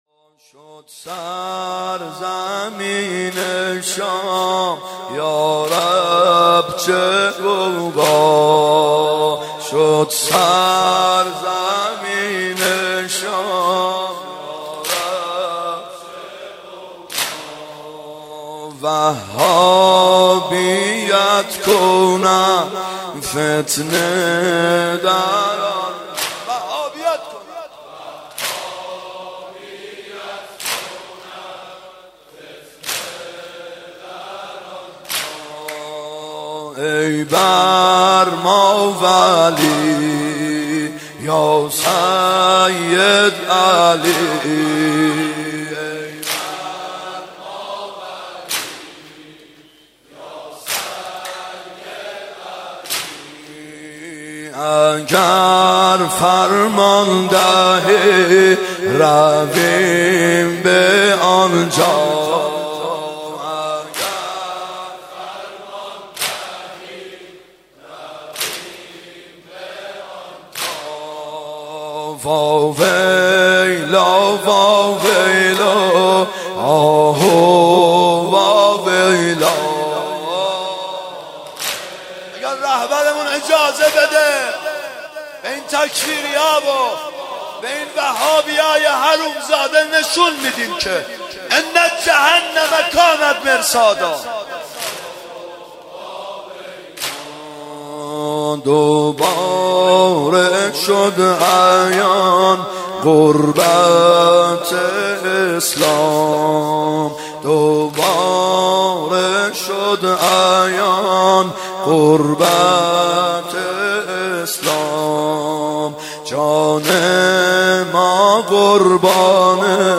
کحرم 92 شب هشتم واحد ( شدسرزمین شام یارب چه غوغا
محرم 92 ( هیأت یامهدی عج)